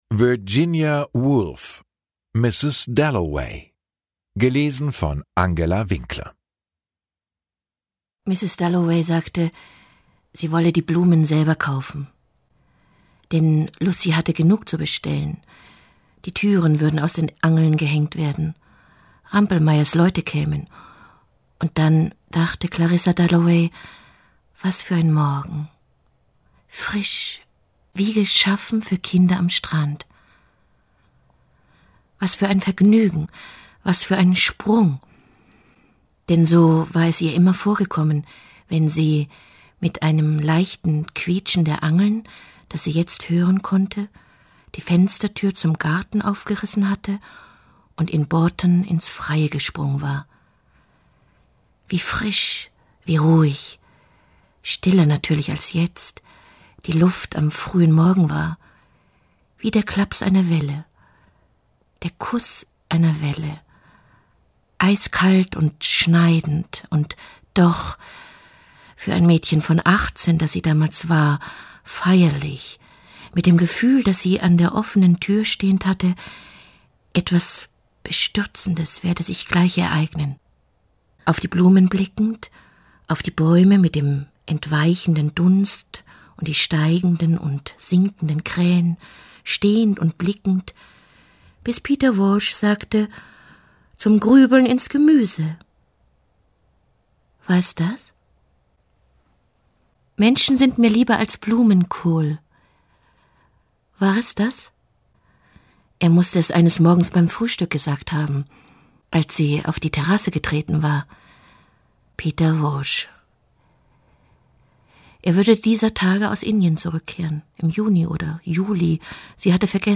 Lesung mit Angela Winkler (1 mp3-CD)
Angela Winkler (Sprecher)